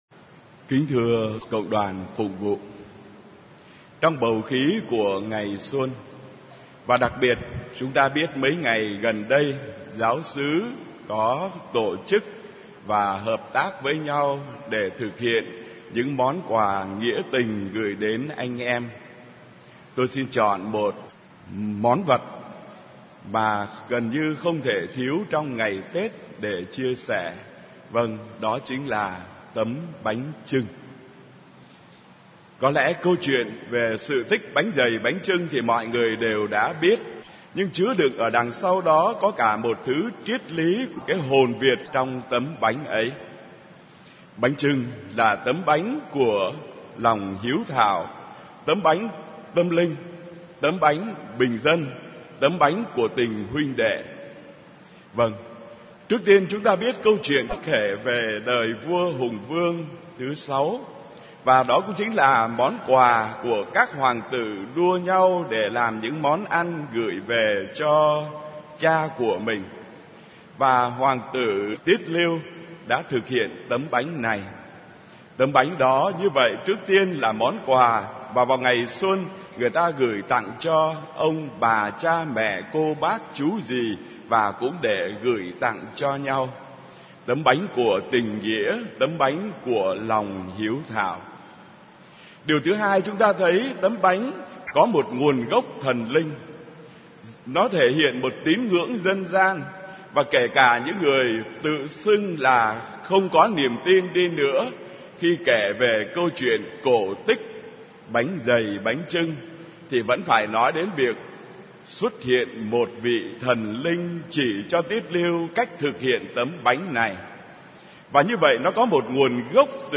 * Thể loại: Nghe giảng